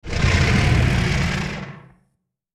File:Sfx creature bruteshark idle 02.ogg - Subnautica Wiki
Sfx_creature_bruteshark_idle_02.ogg